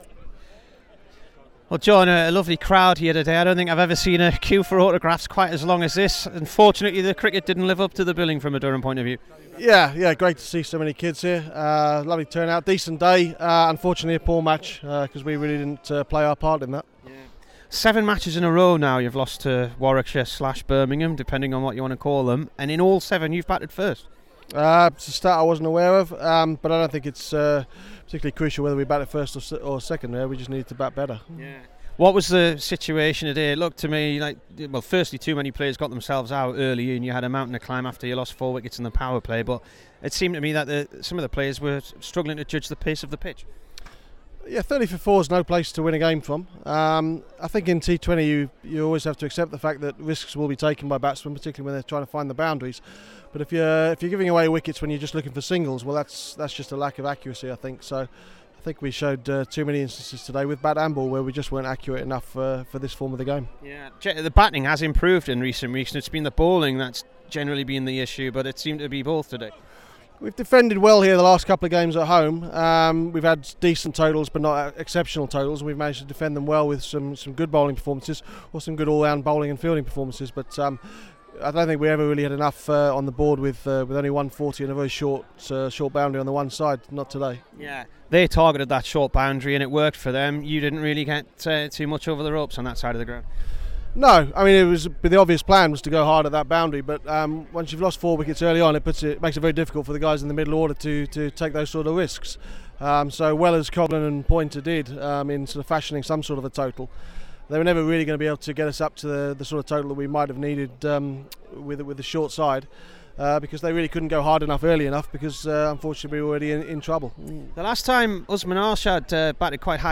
Here's the Durham coach after the defeat by Warwickshire in the T20.